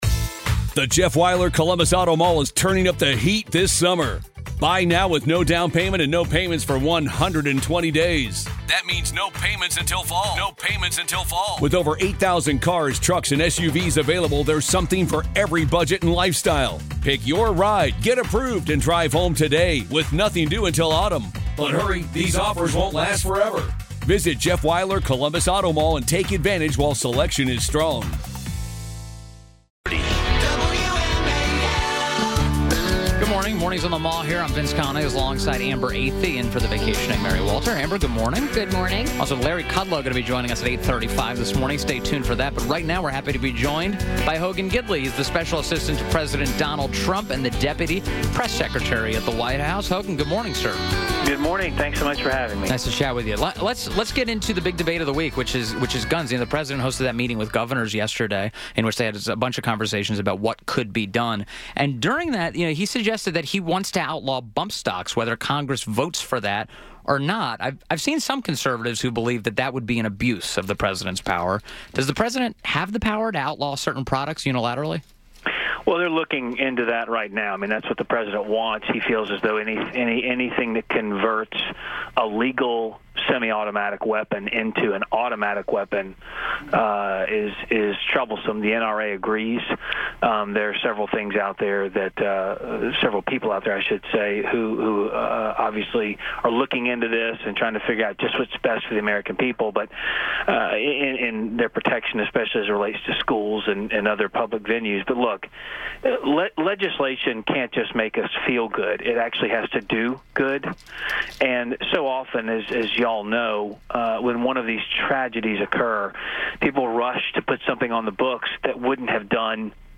WMAL Interview - HOGAN GIDLEY - 02.27.18